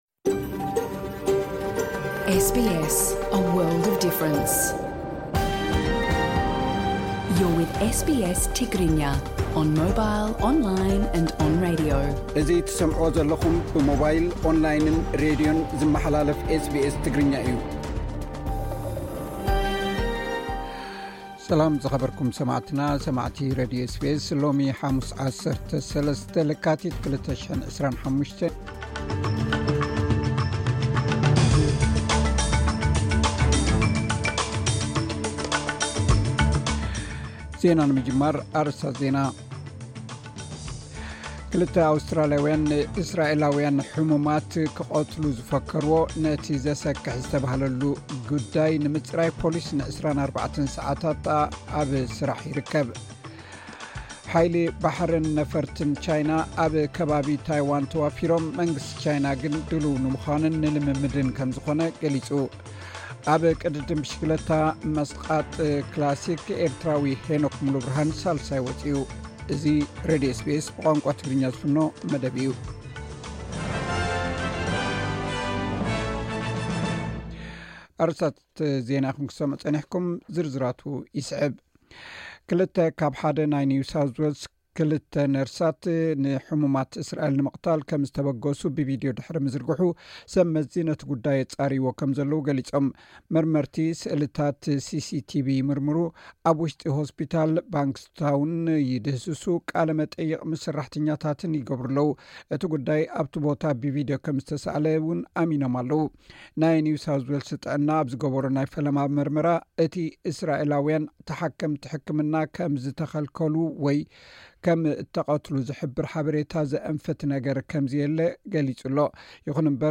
ዕለታዊ ዜና ኤስ ቤስ ትግርኛ (13 ለካቲት 2025)